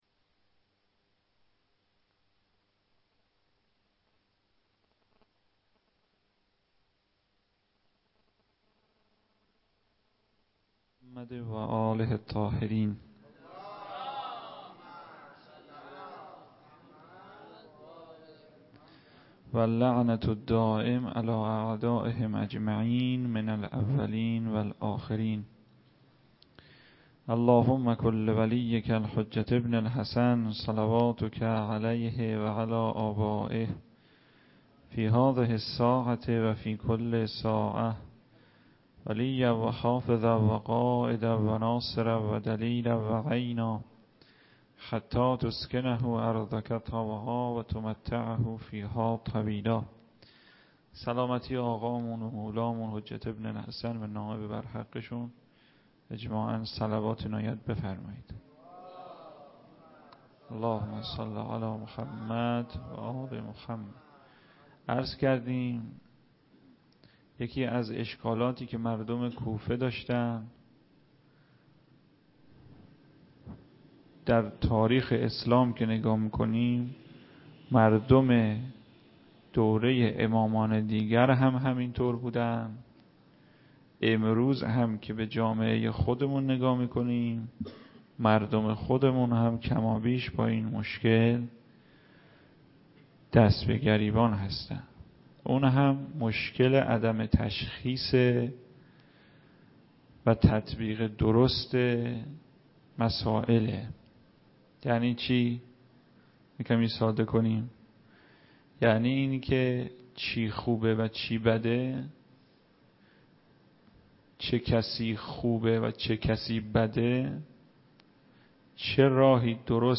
سخنرانی و روضه _حجت الاسلام المسلمین _شب سوم ماه محرم سال 94.mp3
سخنرانی-و-روضه-حجت-الاسلام-المسلمین-شب-سوم-ماه-محرم-سال-94.mp3